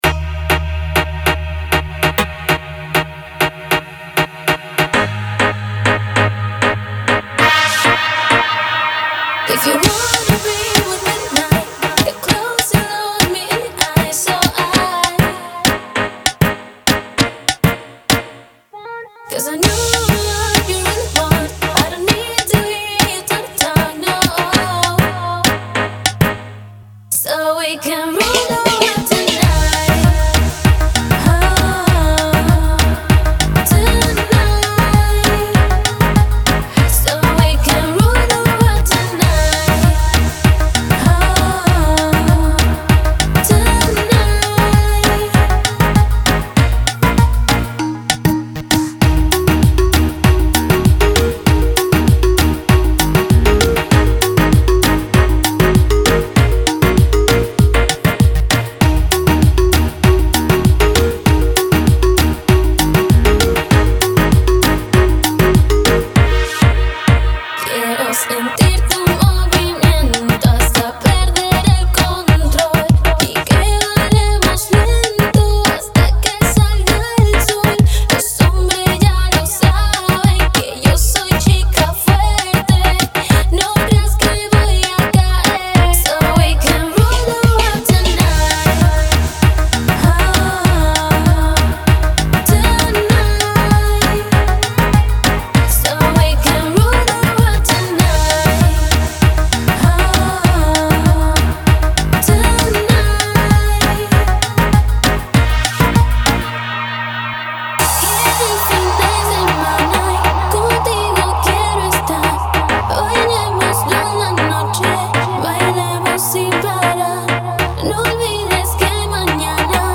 поп-баллада